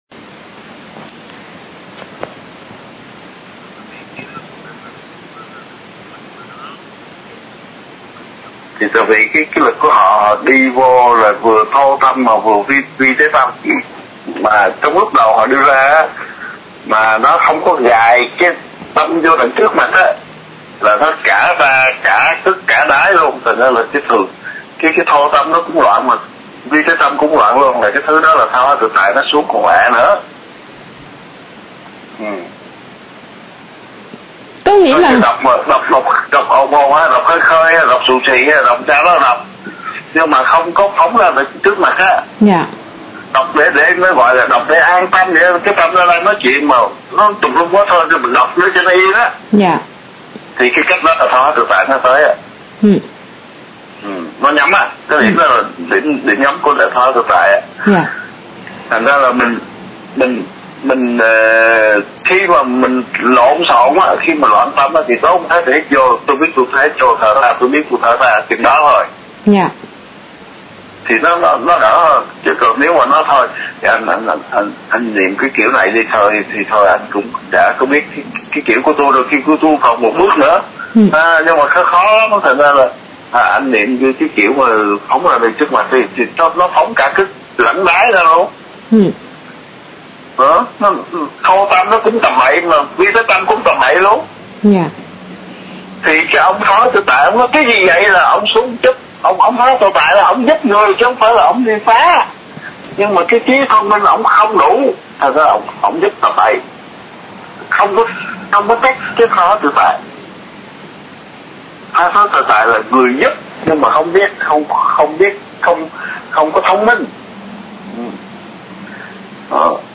10:39 PM Bài Pháp về Tha Hóa Tự Tại: https